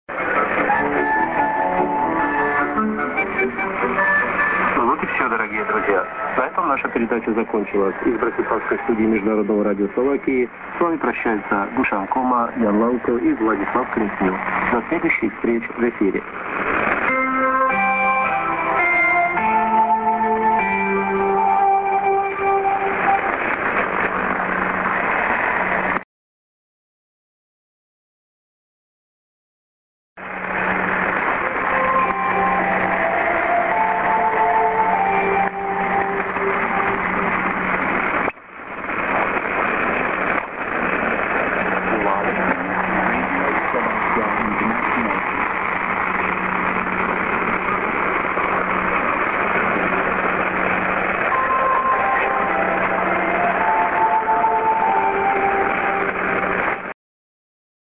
->ID(man)->IS s/off->s/on low signal IS+ID(man):Rep.->
＊西アジア向から西ヨーロッパ向けに変わります。切り替え時間は２０秒で、ここでは途中がカットしてあります。